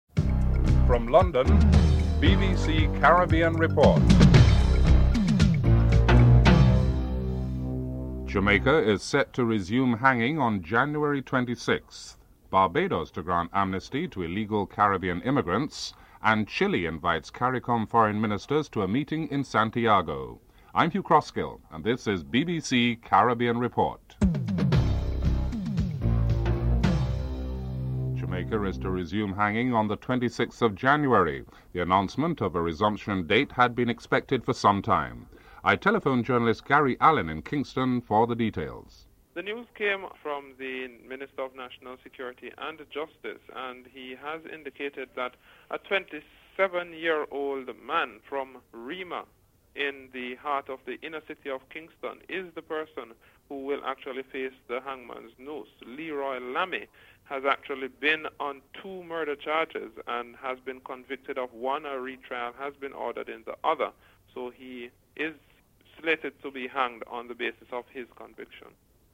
2. Report on the resumption of hanging by Jamaica (00:26-00:38)
5. Interview with David Simmons on Barbados legal reform strategy (03:32-04:47)